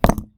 brick-castle.wav